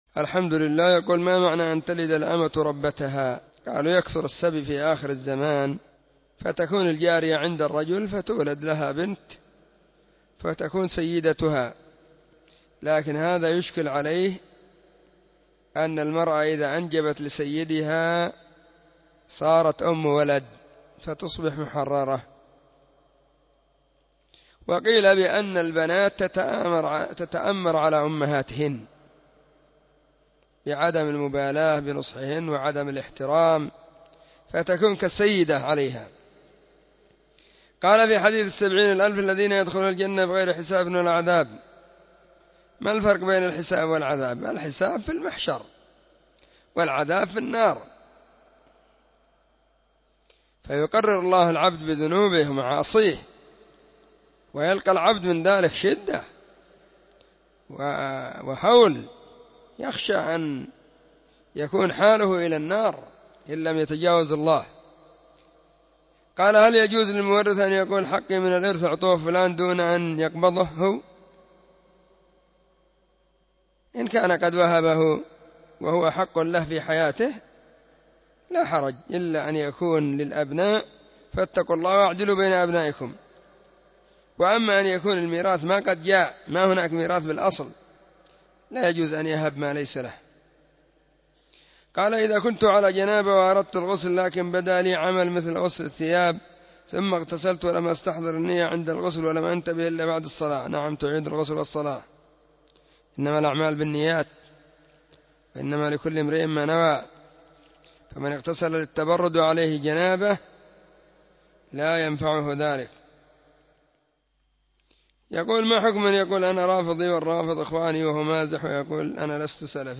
الثلاثاء 30 محرم 1443 هــــ | فتاوى مجموعة | شارك بتعليقك